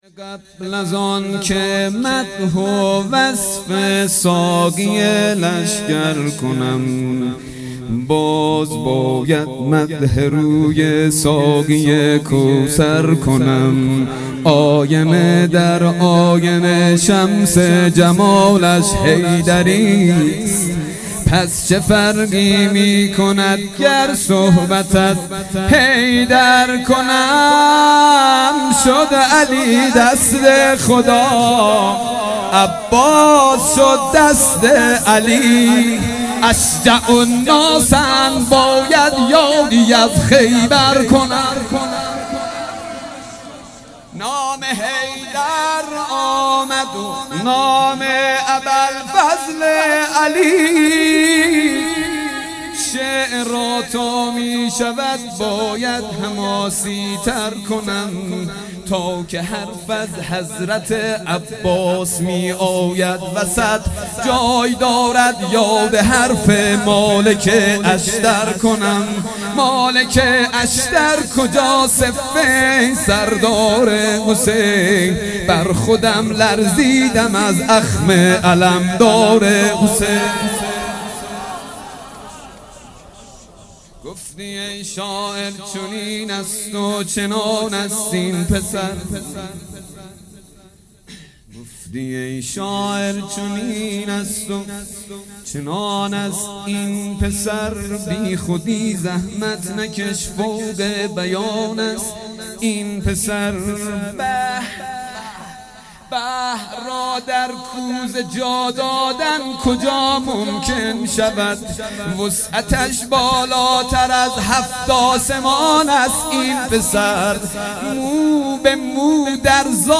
حسینیه بیت النبی
مدح